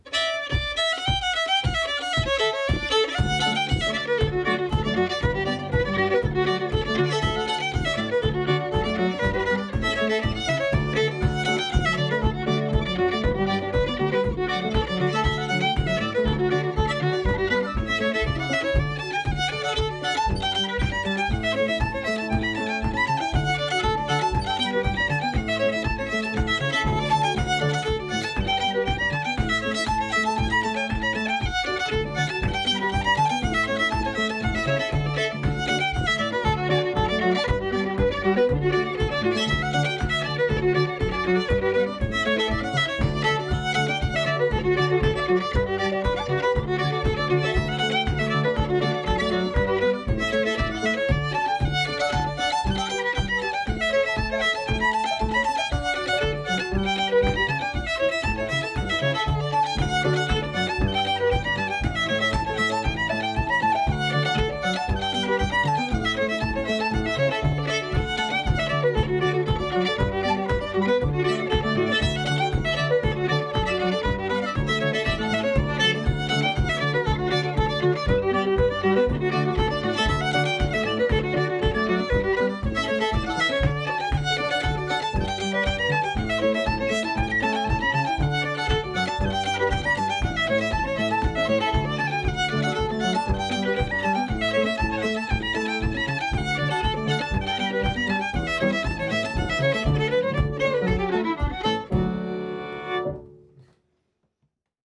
pno